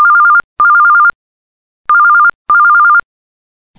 IC-PHONE.mp3